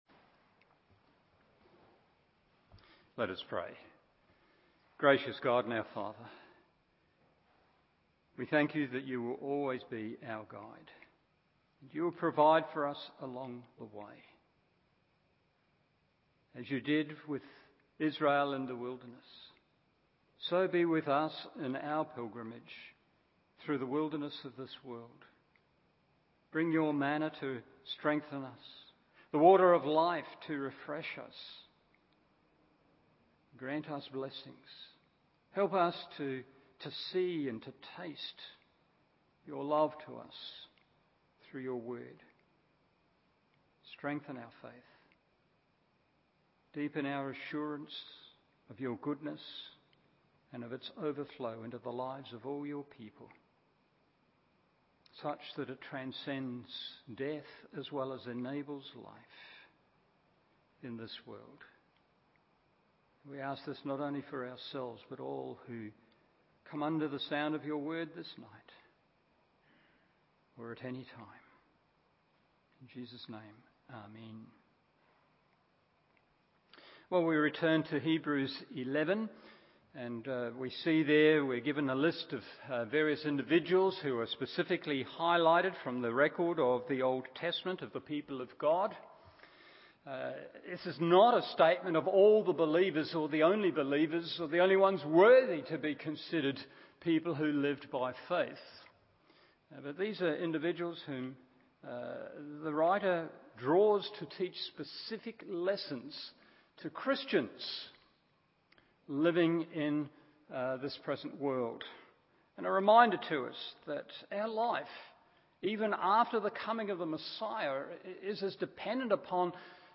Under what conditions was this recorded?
Evening Service Hebrews 11:13-16 1. They died Confidently 2. They lived contentedly 3.